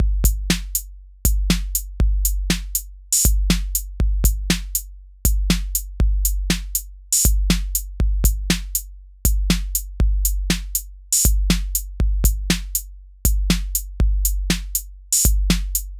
リズムマシン　聞き比べ
自宅録音で使っているリズムマシンの音です
ちなみにエフェクトは一切かけていません。
freeとは思えない音がします。